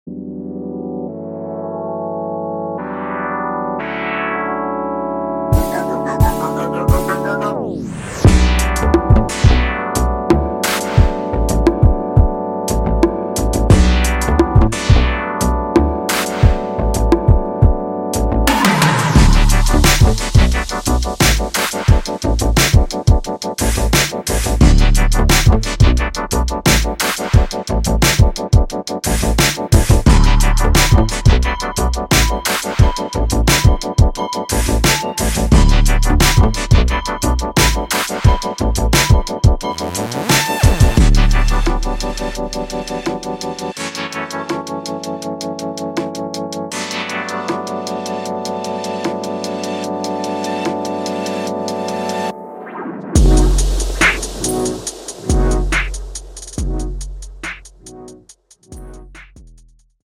Rnb